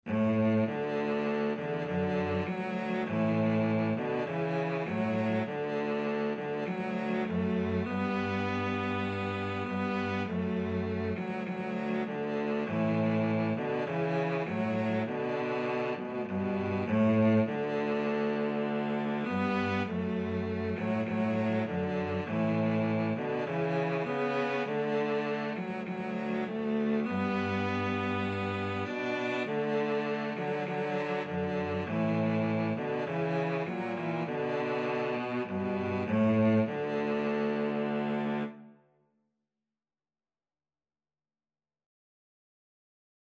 Free Sheet music for Cello Duet
Cello 1Cello 2
D major (Sounding Pitch) (View more D major Music for Cello Duet )
4/4 (View more 4/4 Music)
Scottish